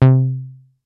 ACID L DRY 1.wav